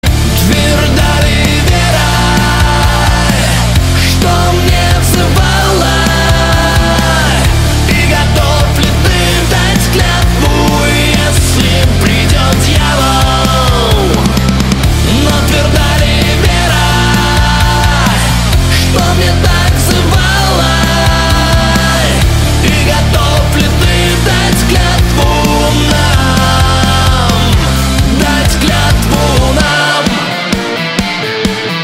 • Качество: 192, Stereo
русский рок
heavy Metal